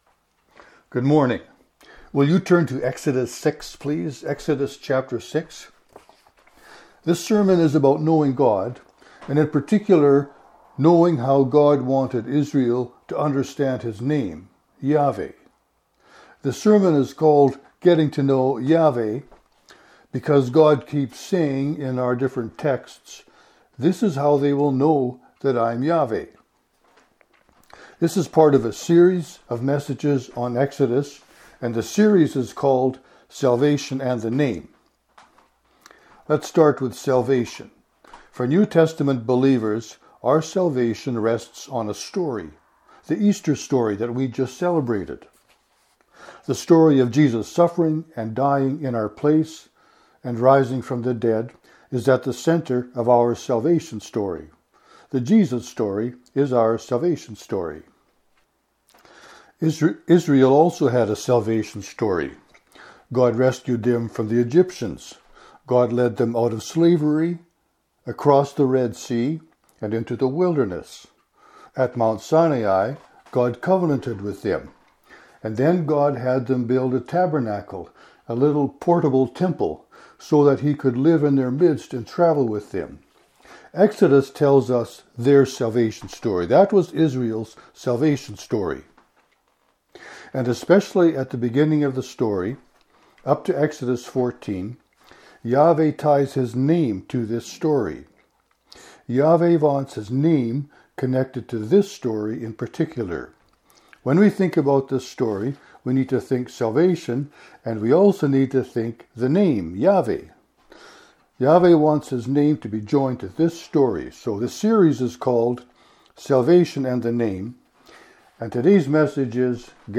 This sermon is about knowing God, and in particular knowing how God wanted Israel to understand his name “Yahweh.”